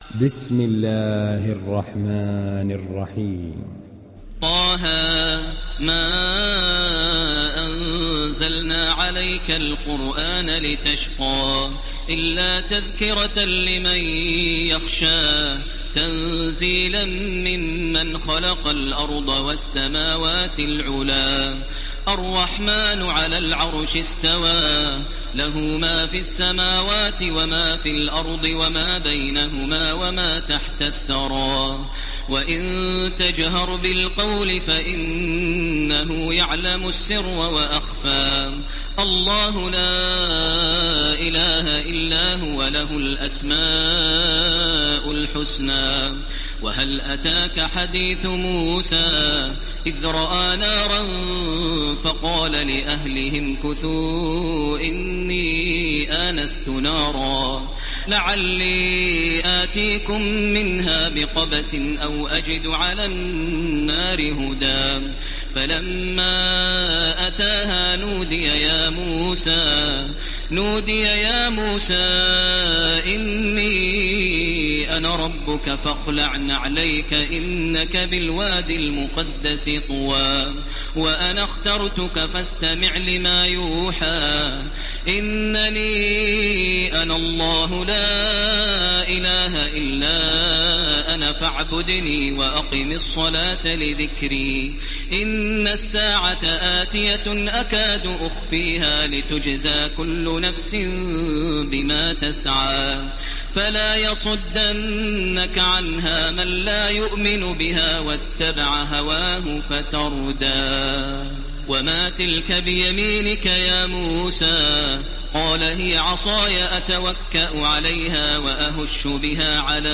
دانلود سوره طه تراويح الحرم المكي 1430